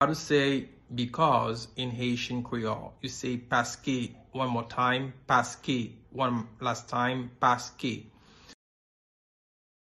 Listen to and watch “Paske” audio pronunciation in Haitian Creole by a native Haitian  in the video below:
3.How-to-say-Because-in-Haitian-Creole-–-Paske-pronunciation-.mp3